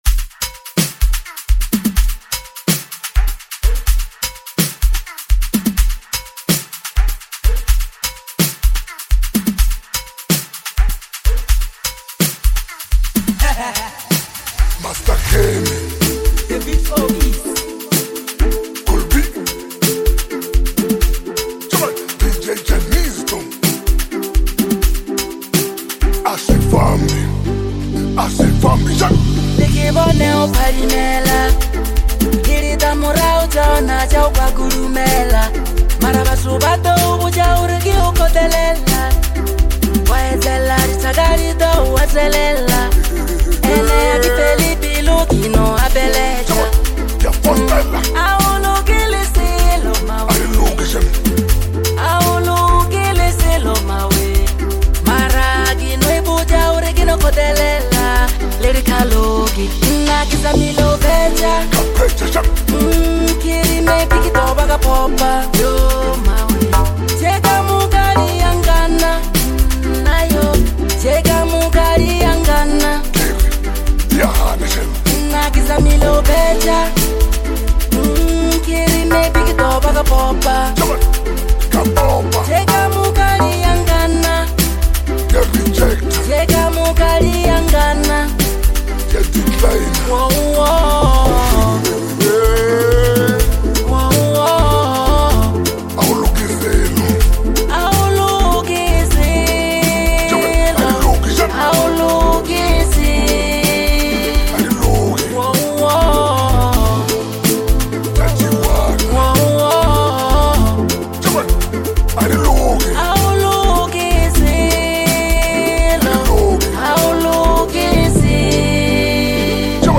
smooth vocal delivery